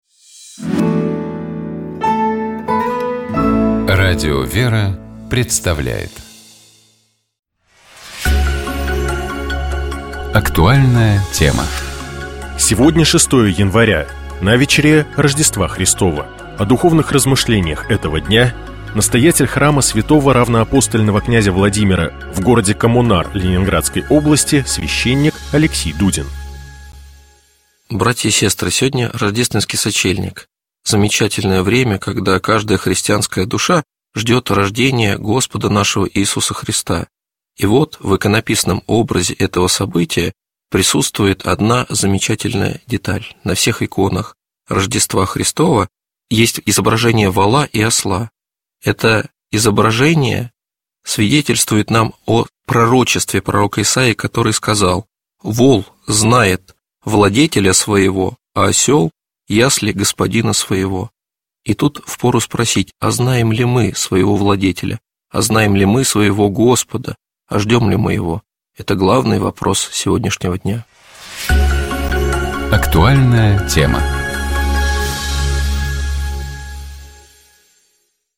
В послании к коринфянам апостол Павел говорит о вере, надежде и любви, но «любовь из них больше». Что такое настоящая любовь – размышляет телеведущий, доктор биологических наук Николай Дроздов.